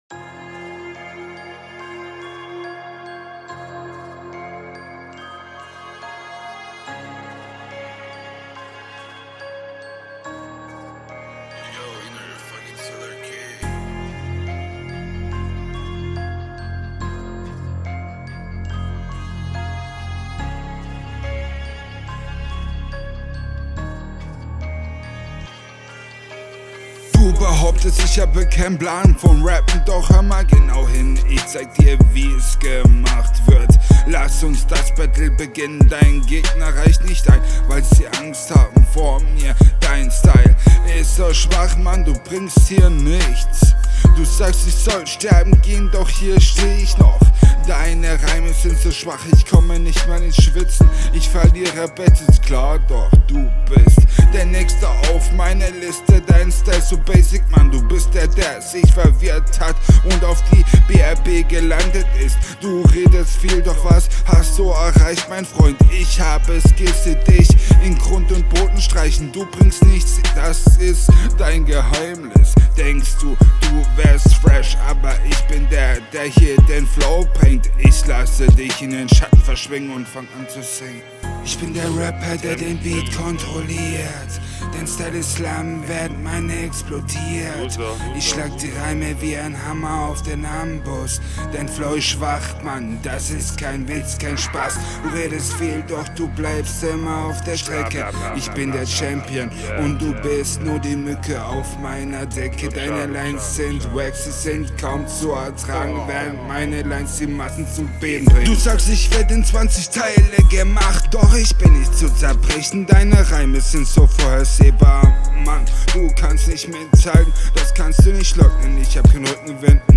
Fire Homie 🔥🔥🔥🔥🔥 Der Flow hat mein Leben.